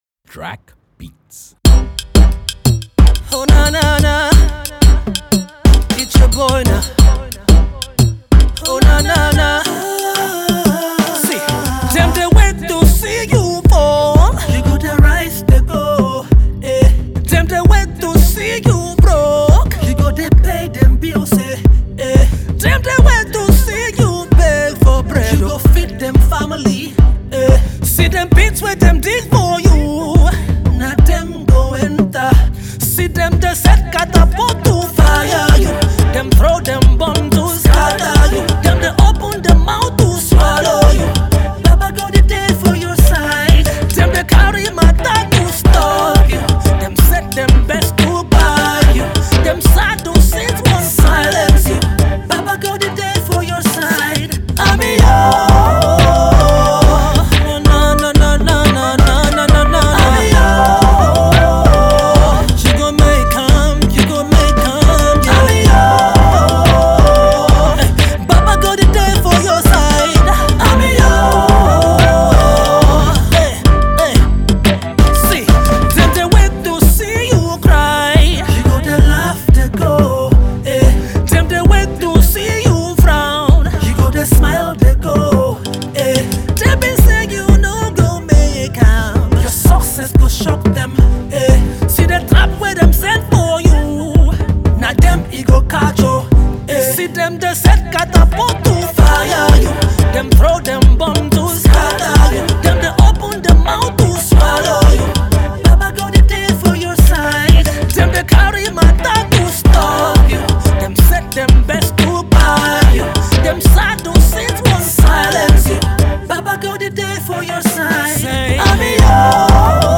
spirit-lifting worship song titled Kosi
melodious and fortifying track